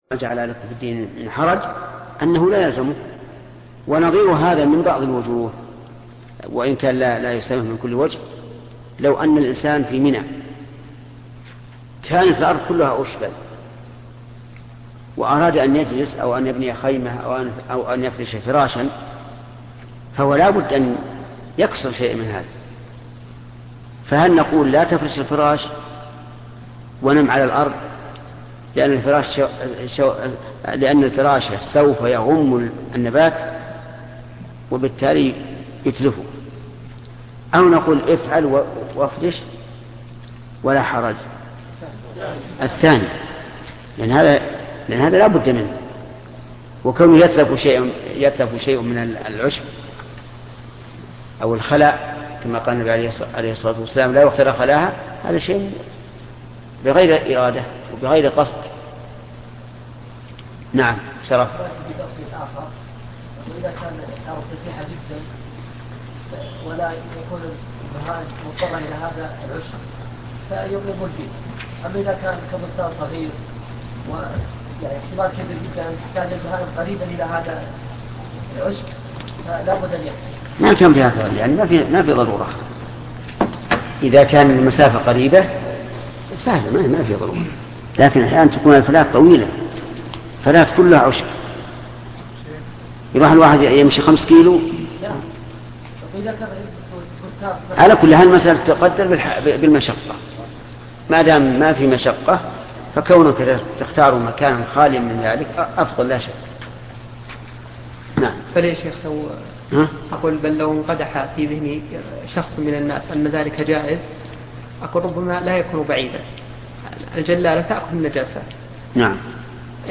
صحيح البخاري شرح الشيخ محمد بن صالح العثيمين الدرس 62